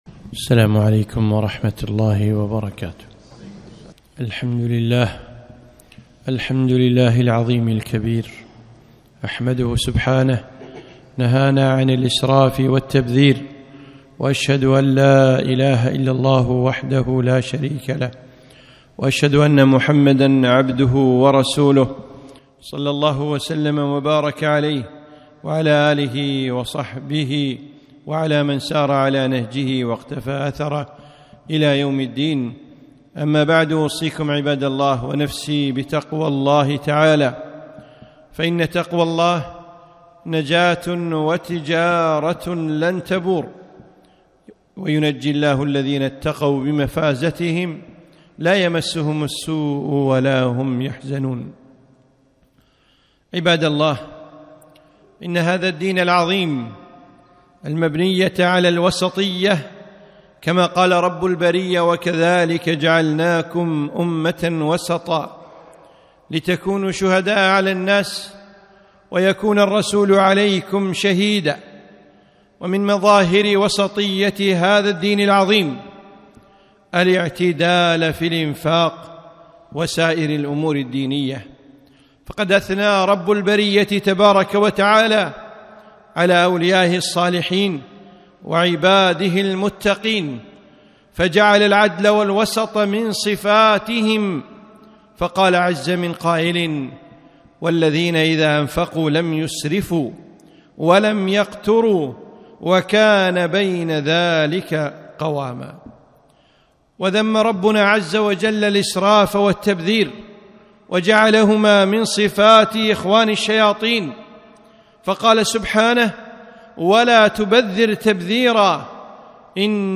خطبة - إياك والسرف